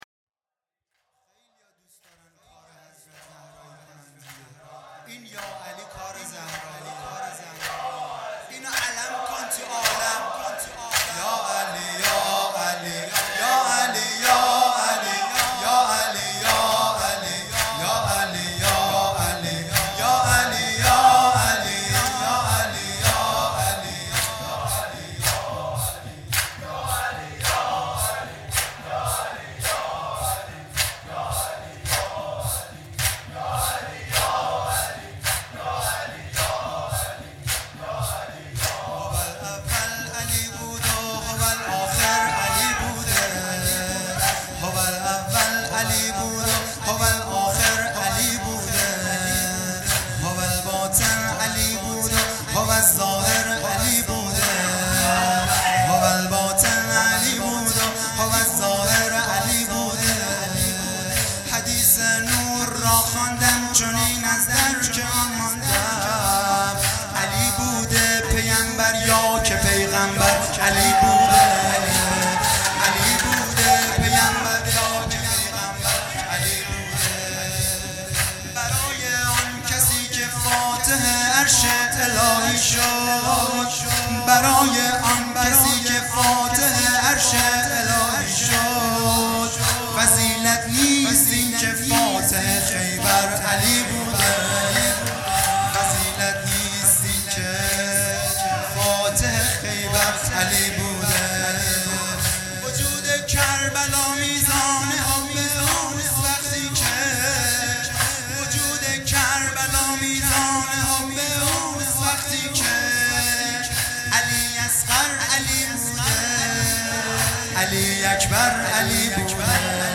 هیئت دانشجویی فاطمیون دانشگاه یزد - تک